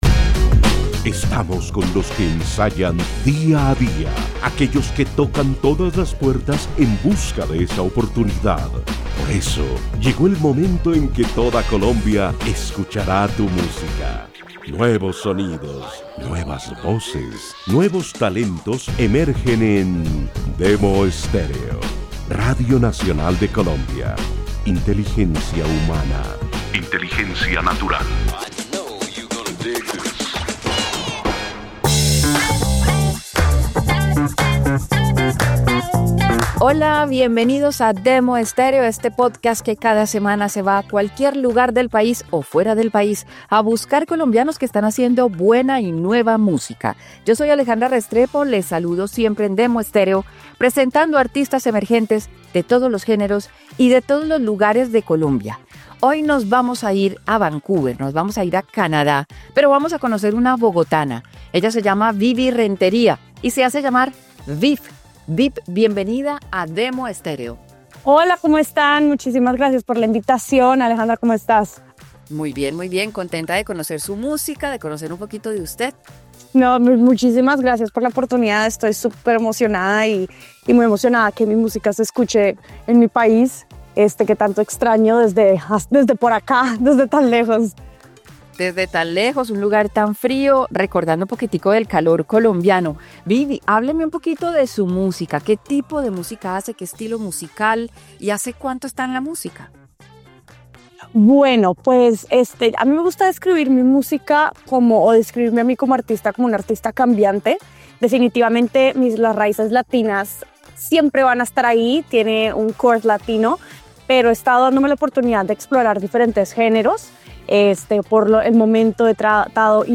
..Demo estéreo pódcast.